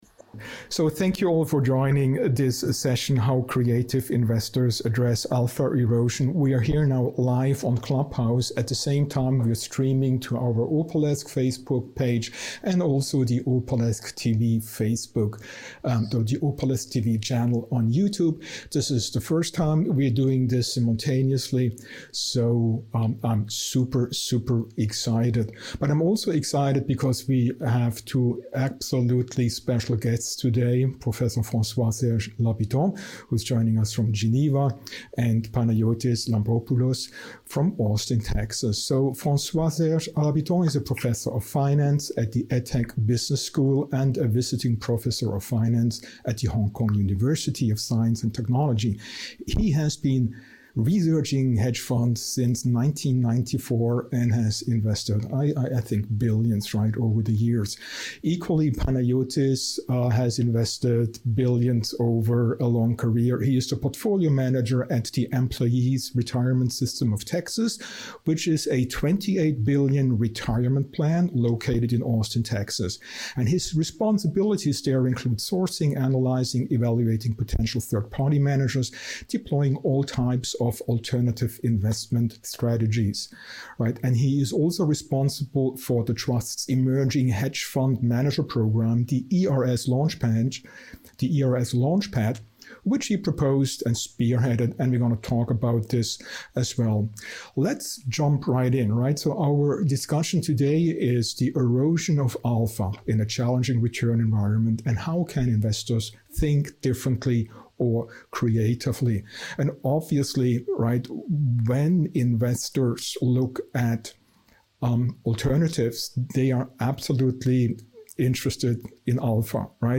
How Creative Investors Address Alpha Erosion - Masterclass in Skillful Investing